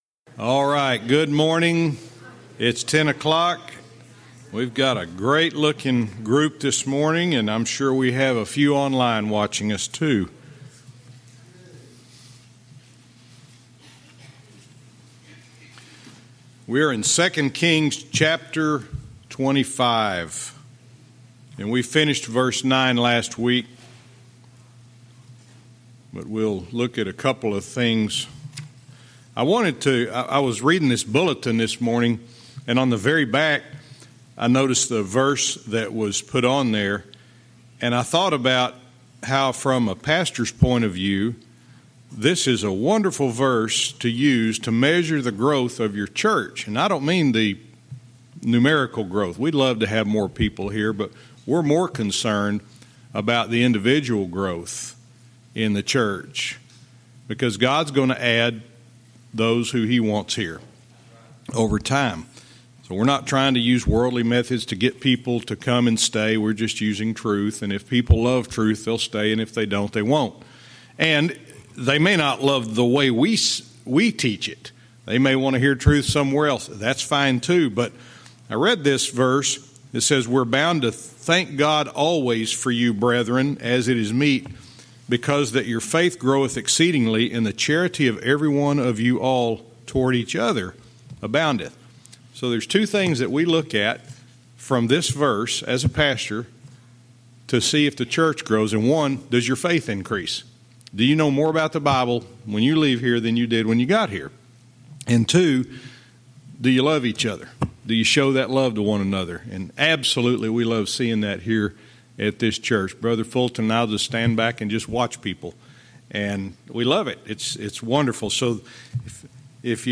Verse by verse teaching - 2 Kings 25:10-12